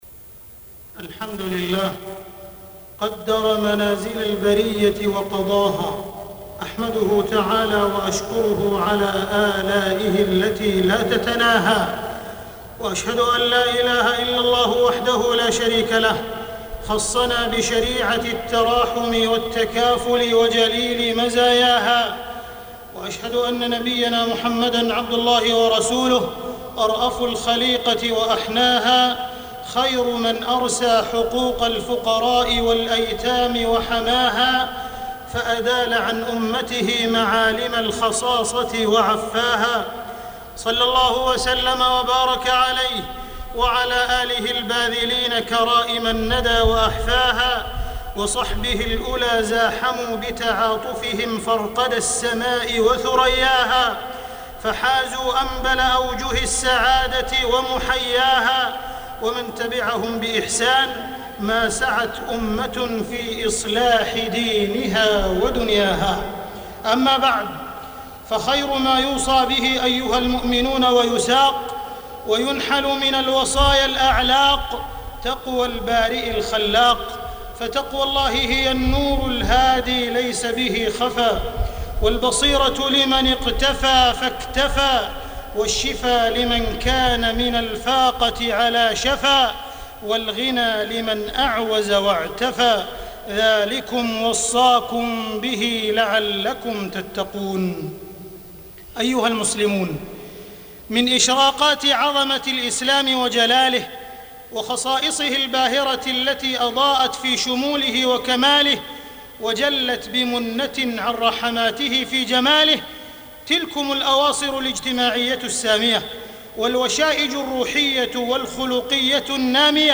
تاريخ النشر ١٢ شوال ١٤٢٧ هـ المكان: المسجد الحرام الشيخ: معالي الشيخ أ.د. عبدالرحمن بن عبدالعزيز السديس معالي الشيخ أ.د. عبدالرحمن بن عبدالعزيز السديس إسعاف ذوي الحاجات